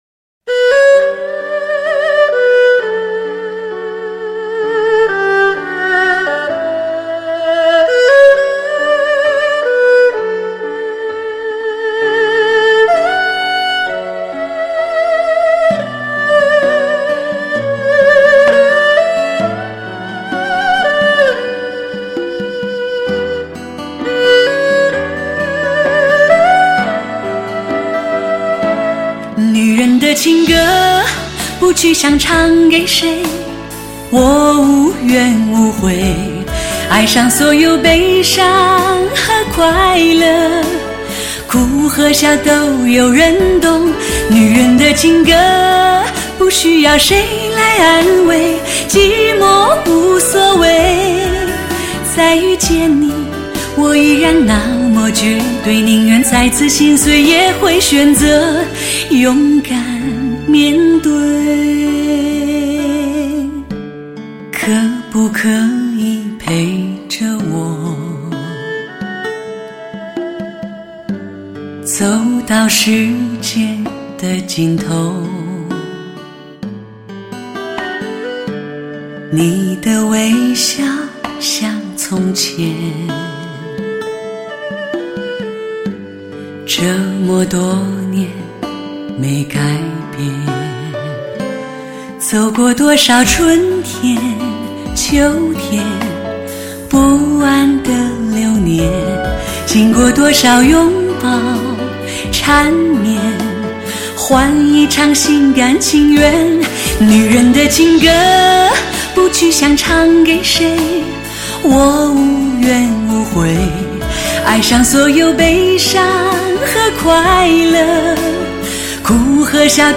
专辑格式：DTS-CD-5.1声道
为低音质MP3